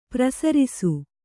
♪ prasarisu